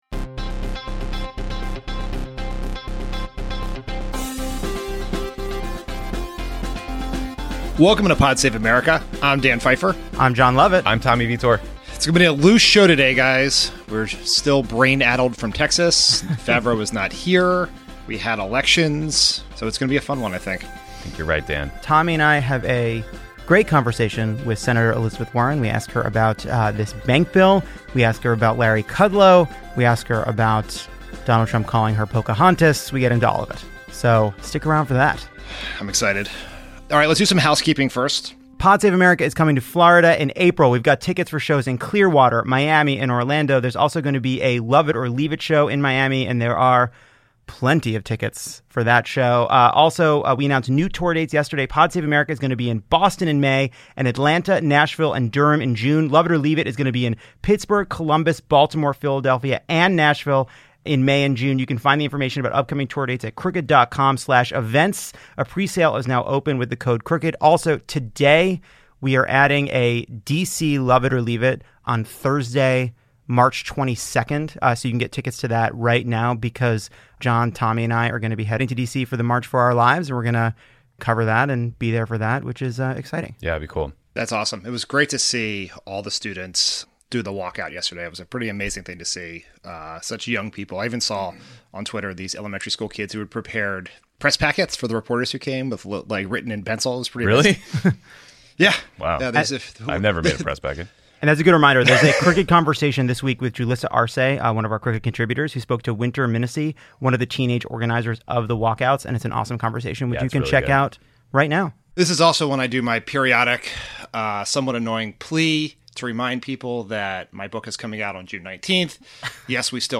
Democrats win another special election, Republicans flail around for a response, and Trump sends Tillerson to the Rexit in a major shakeup of the national security team. Then Lovett and Tommy talk to Senator Elizabeth Warren.